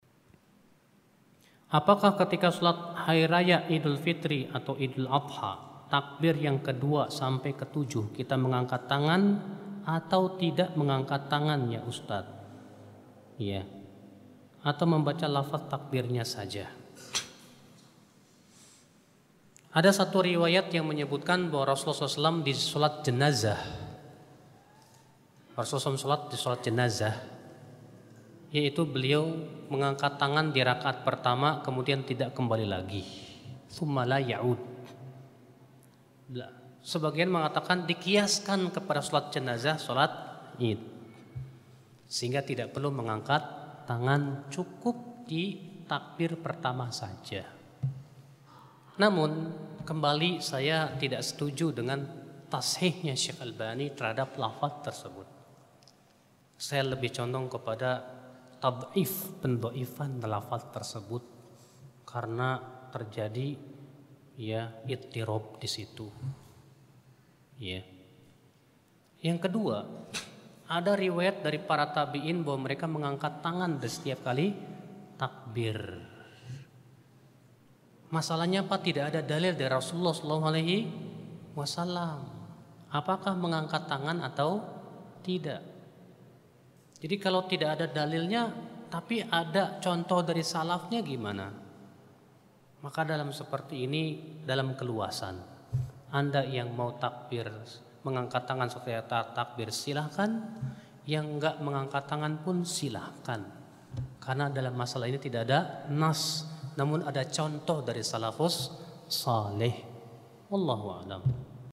Dari sesi Tanya-Jawab, Kajian Renungan Takbir, 10 April 2016 di Masjid Al Kautsar, Polda Metro Jaya, Jakarta.